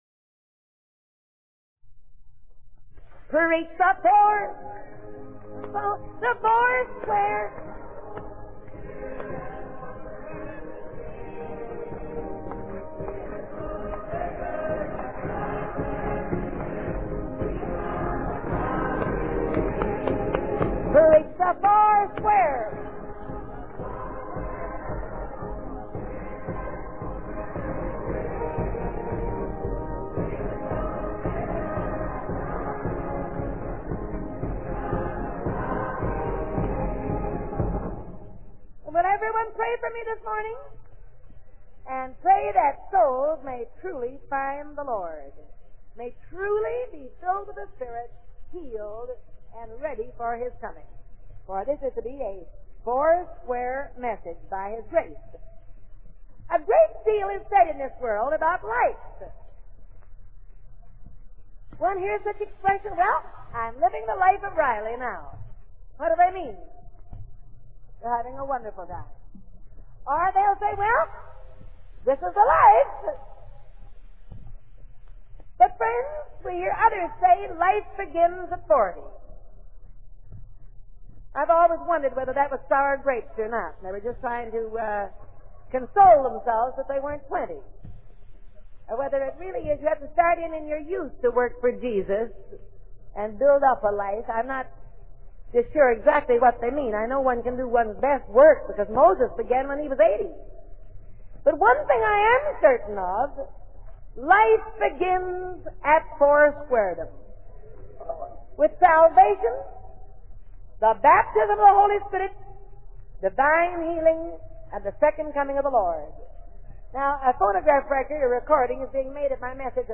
Listen to some of Aimee Semple McPherson’s original sermons.
Hear her preach, and you’ll understand why her voice was one of the most recognizable in the world.